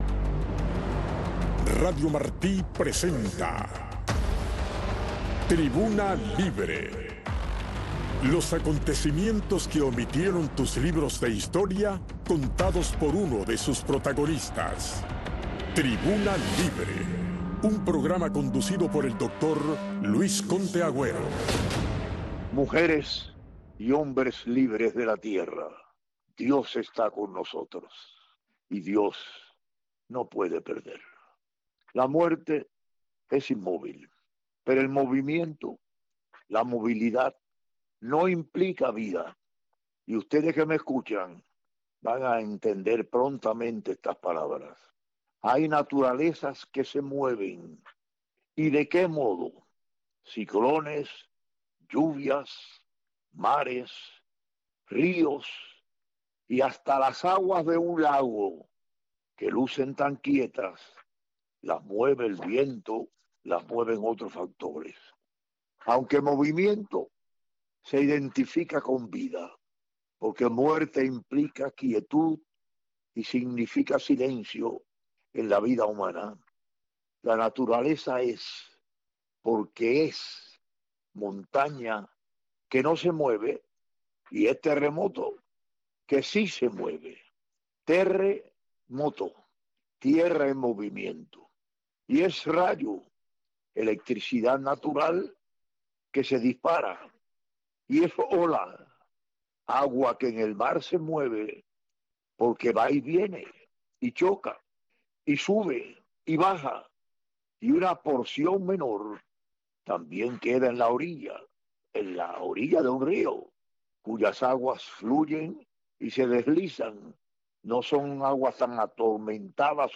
Radio Marti presenta Tribuna Libre. Los acontecimientos que omitieron tus libros de historia, contados por uno de sus protagonistas. Un programa conducido por el Doctor: Luis Conté Agüero.